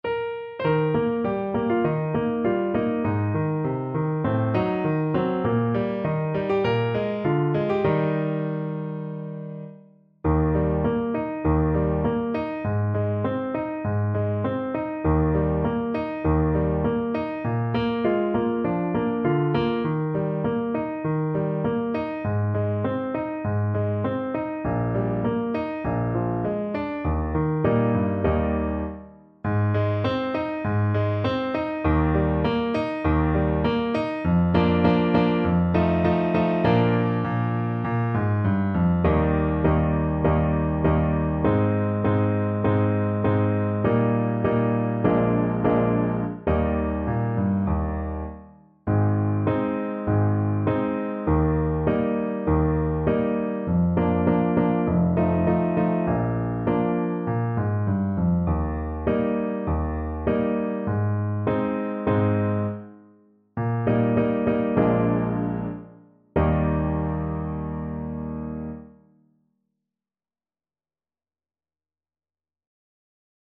Trumpet
Traditional Music of unknown author.
Bb4-D6
Eb major (Sounding Pitch) F major (Trumpet in Bb) (View more Eb major Music for Trumpet )
~ = 100 Moderato